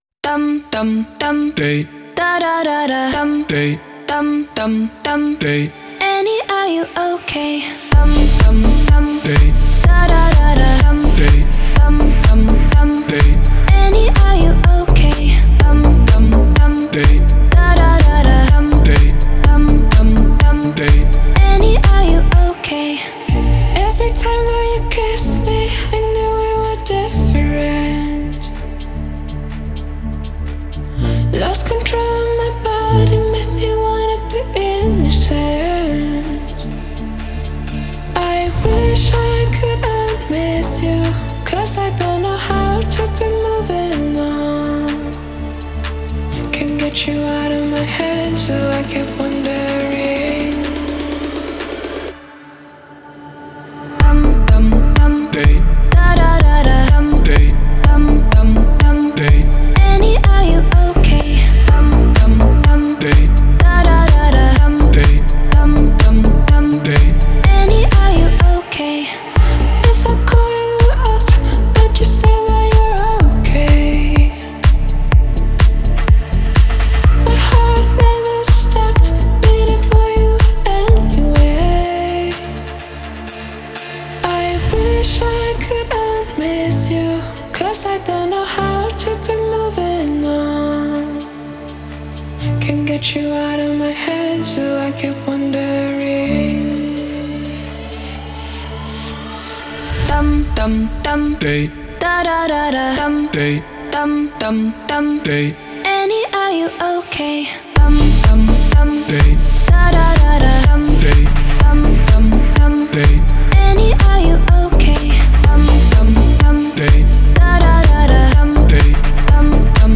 это энергичная электронная танцевальная композиция